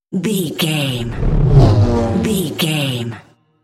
Scifi pass by vehicle
Sound Effects
futuristic
high tech
pass by
vehicle